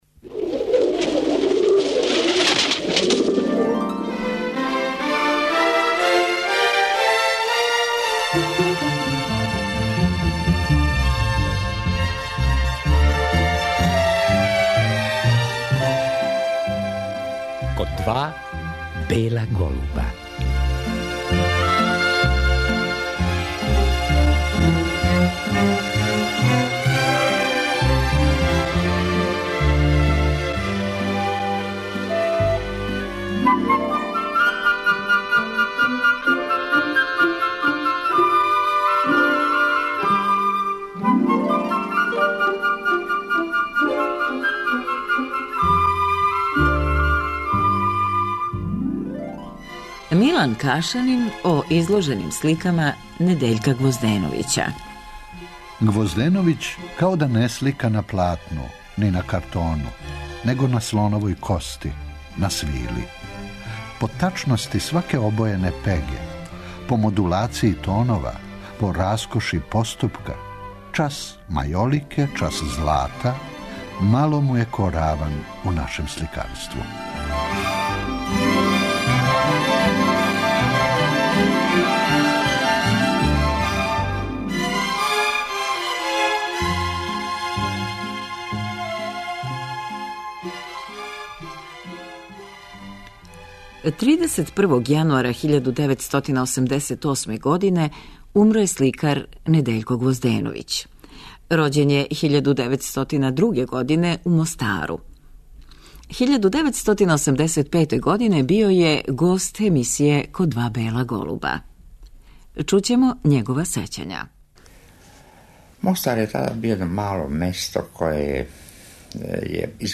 У Галерији РТС-а отоворена је изложба Недељка Гвозденовића до 24. новембра. Тим поводом, слушаћемо сећања сликара, која су за ову емисију снимљена 1986. године.